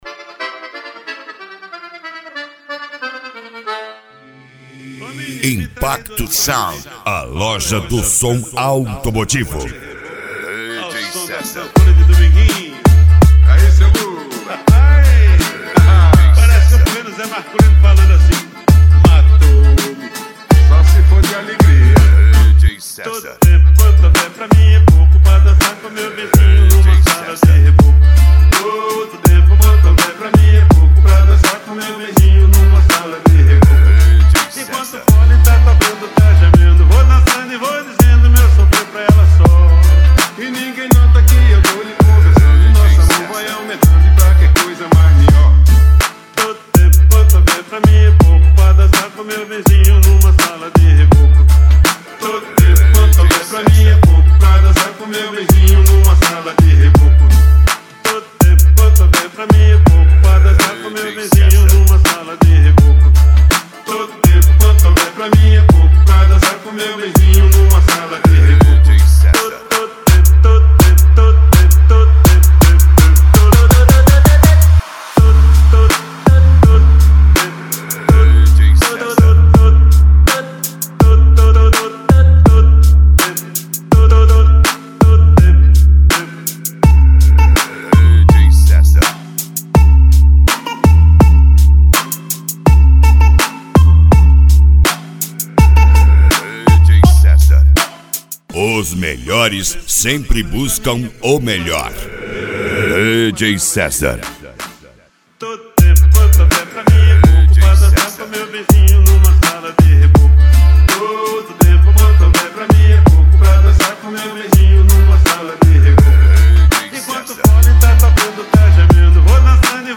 Funk Nejo
Mega Funk